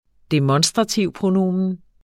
Udtale [ deˈmʌnsdʁɑˌtiwˀ- ]